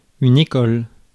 Ääntäminen
Ääntäminen France: IPA: [e.kɔl] Haettu sana löytyi näillä lähdekielillä: ranska Käännös Substantiivit 1. училище {n} 2. школа {f} Muut/tuntemattomat 3. факултет {m} 4. учи́лище {n} 5. шко́ла {f} Suku: f .